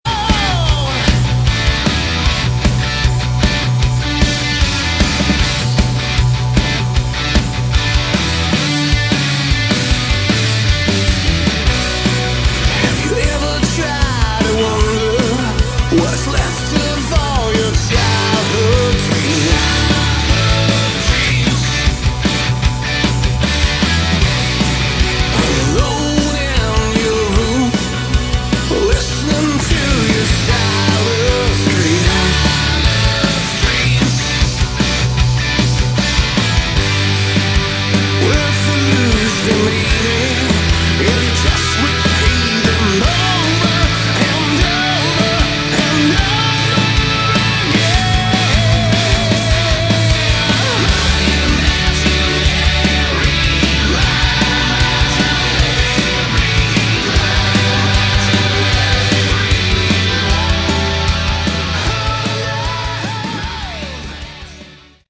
Category: Hard Rock
Vox
Guitars, Bass, Keyboards
Drums